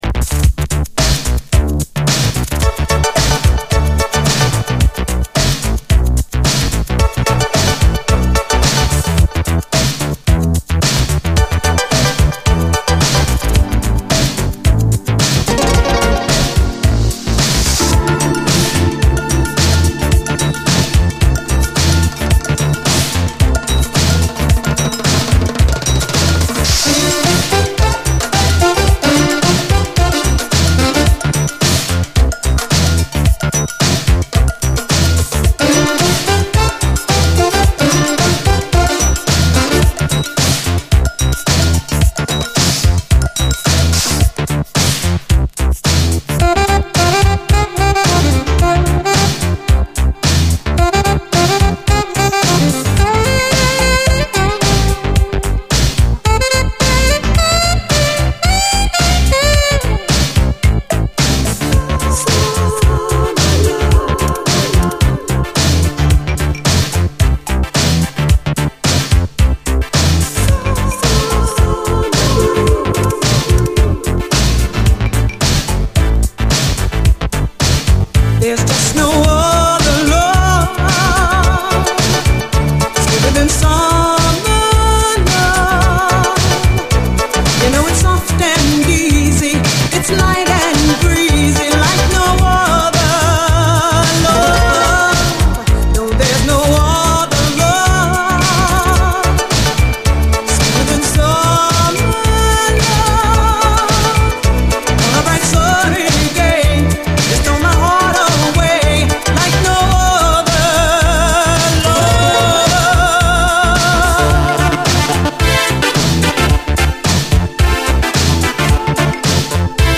SOUL, 70's～ SOUL, DISCO
カナダ産メロウ＆ジャジーな80’Sエレクトリック・シンセ・ブギー！
スムースなシンセが気持ちいい、メロウ＆ジャジーなエレクトリック・シンセ・ブギー！
メロディアス＆ソウルフルな女性ヴォーカルがよい！